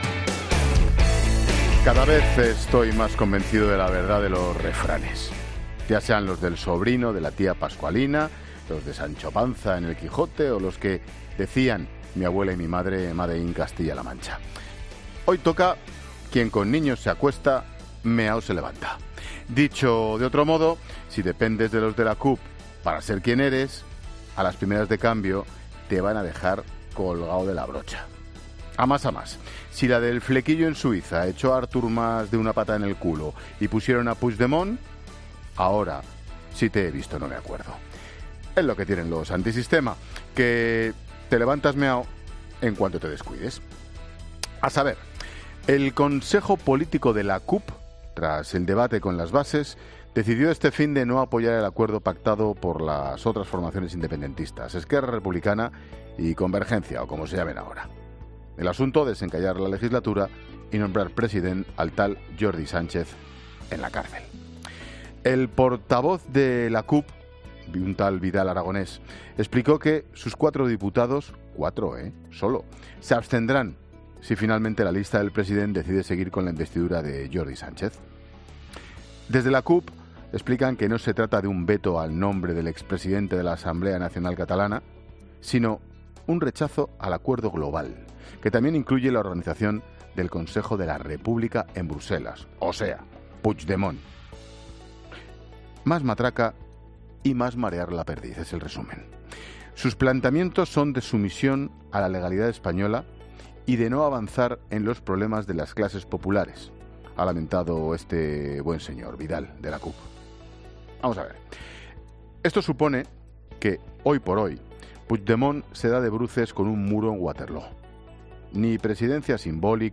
AUDIO: El comentario de Ángel Expósito.